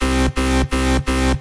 Powerstomp 1 Lead (E大调/170 BPM)
标签： 循环 性交 英国的性交 狂欢 Powerstomp 170-BPM E大调 采样
声道立体声